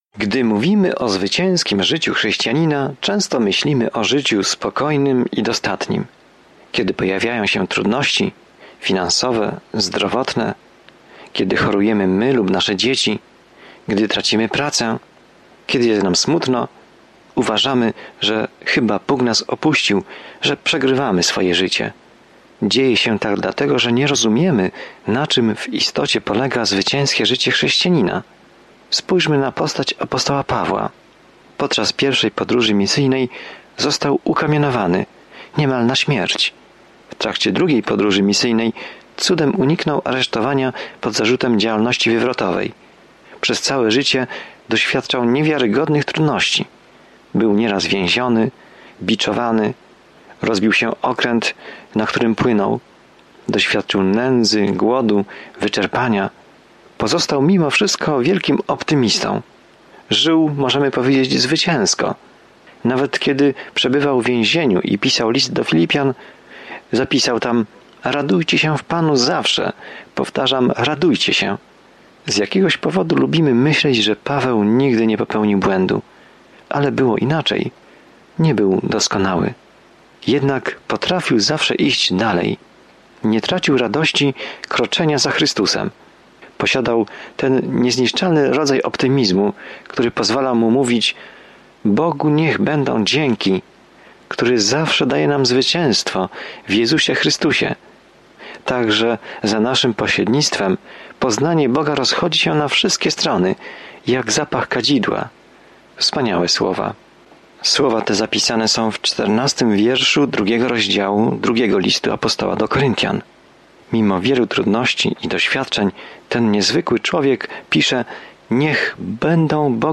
Codziennie podróżuj przez 2 List do Koryntian, słuchając studium audio i czytając wybrane wersety ze słowa Bożego.